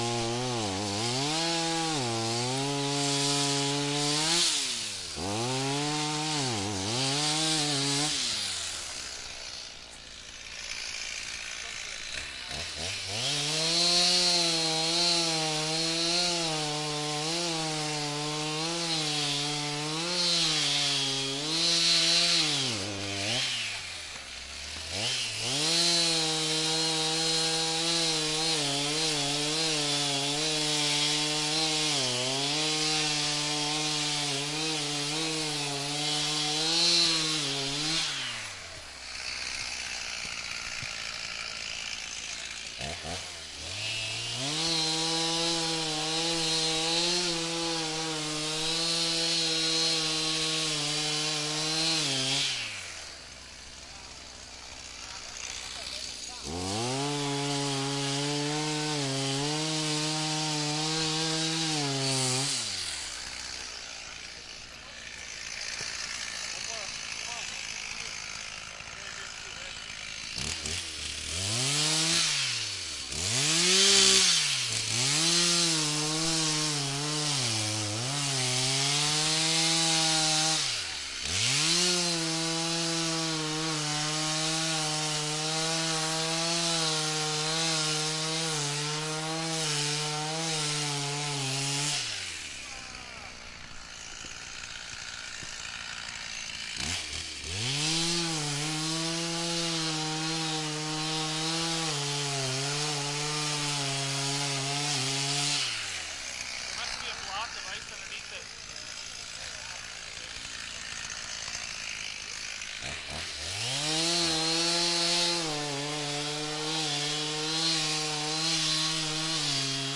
随机 " 电锯锯开附近的冰块
描述：电锯锯冰附近。弗拉克
Tag: 电锯